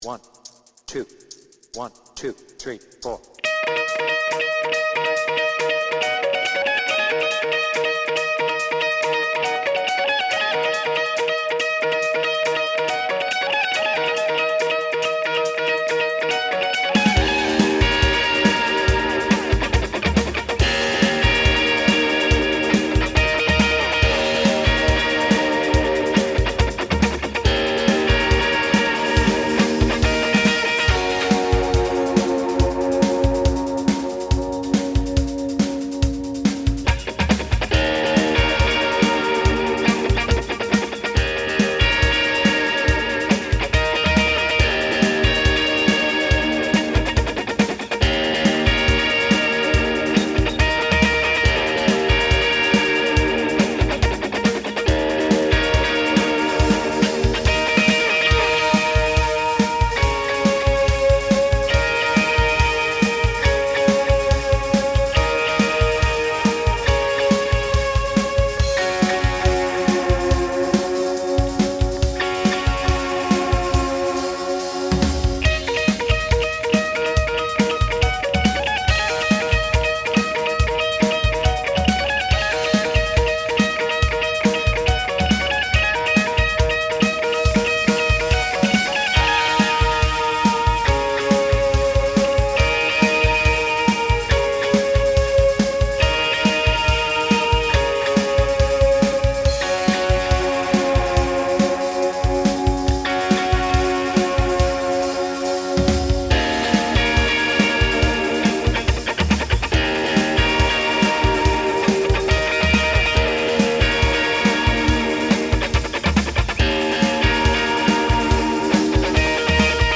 BPM : 140
Tuning : Eb
Without vocals